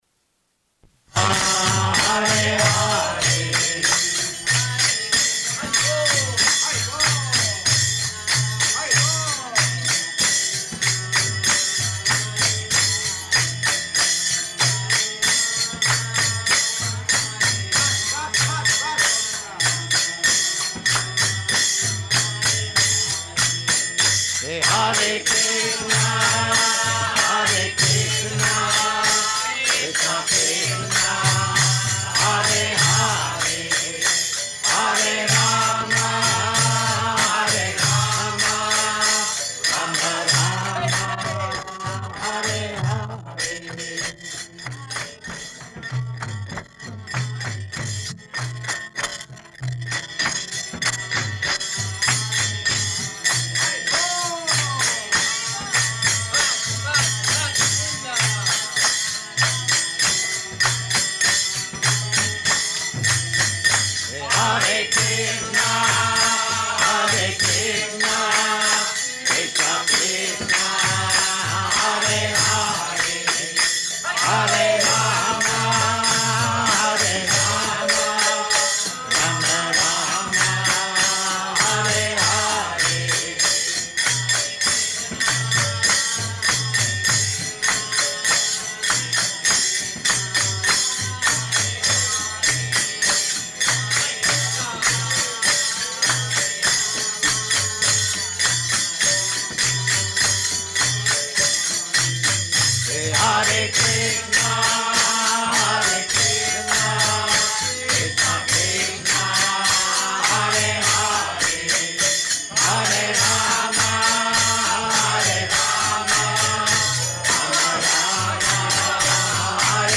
Kīrtana Excerpt [partially recorded]
Location: Seattle